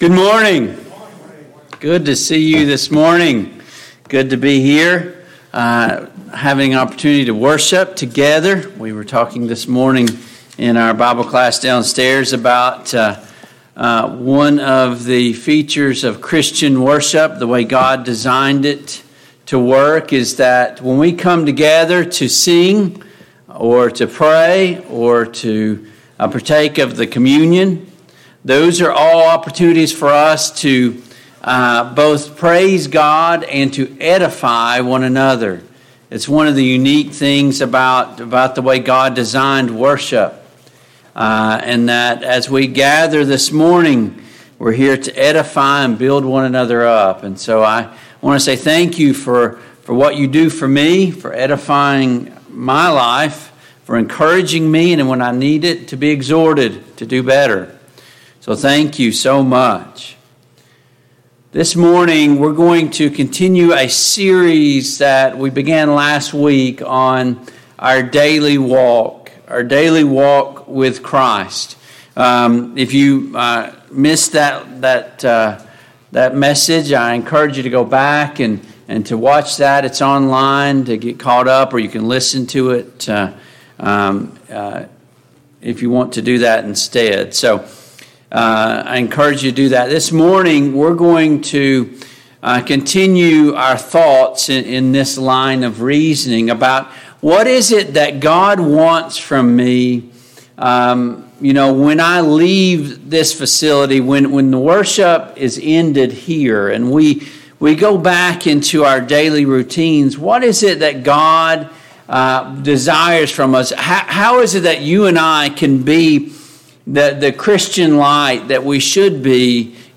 Our Daily Walk Service Type: AM Worship Download Files Notes Topics: Prayer « 6.